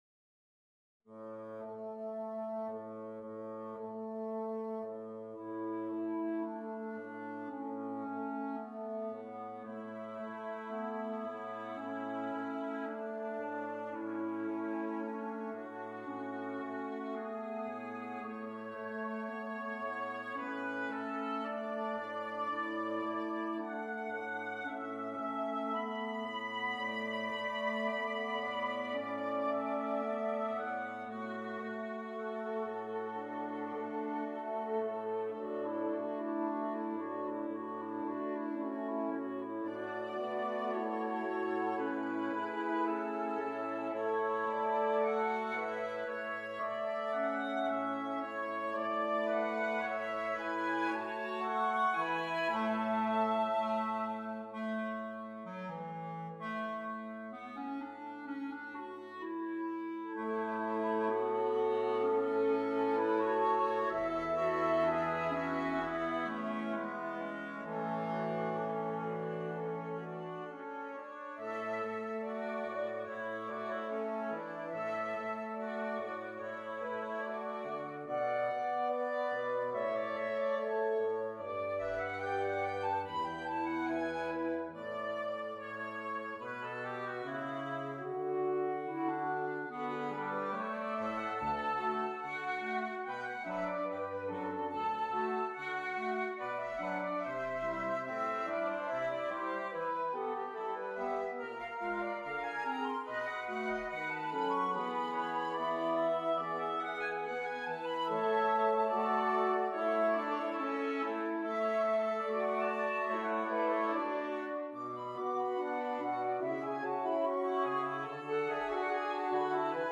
wind quintet